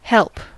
help: Wikimedia Commons US English Pronunciations
En-us-help.WAV